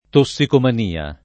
tossicomania [ to SS ikoman & a ] s. f.